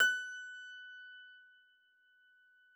53j-pno20-F4.wav